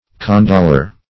Condoler \Con*dol"er\, n. One who condoles.